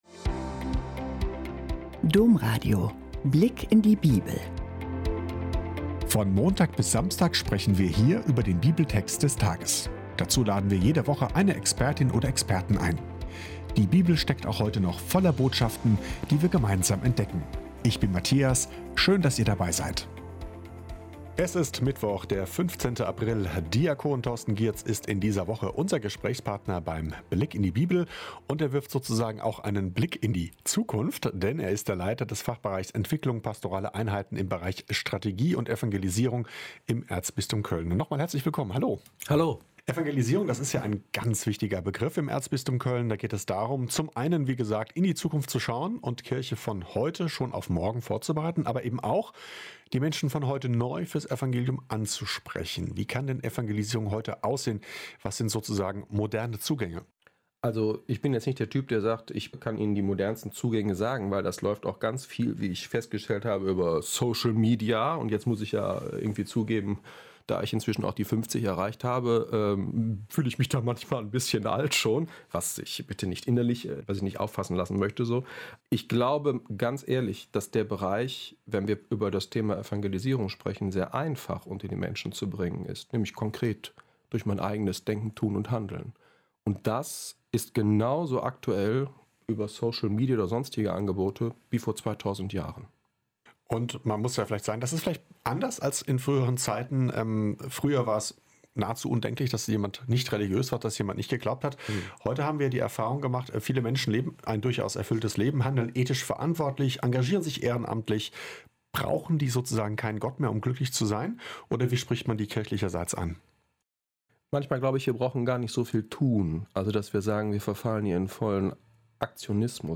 Glaube, der wirklich verändert - Gespräch